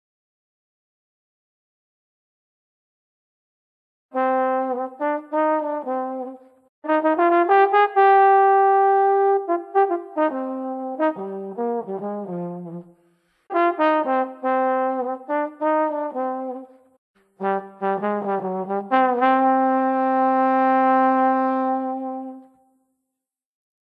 Jazz_Trombone.mp3